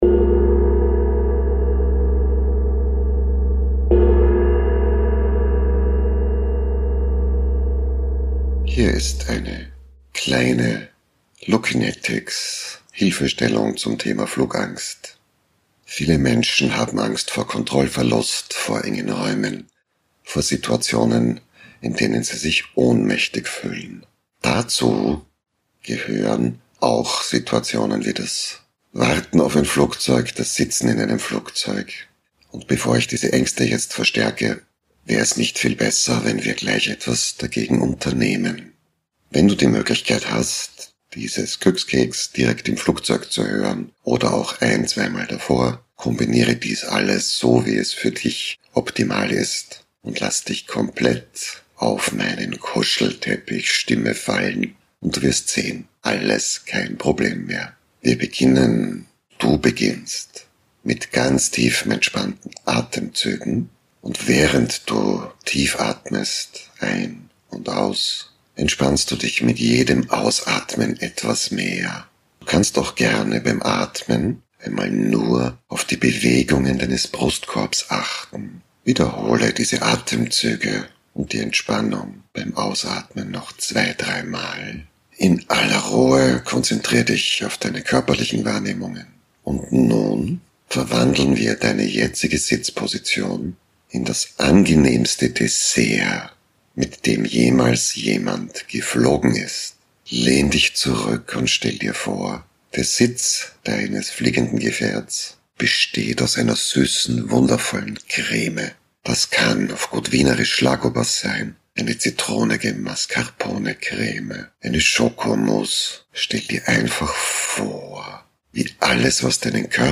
dich – kombiniert mit einer sanften Meditation, die dich beruhigt,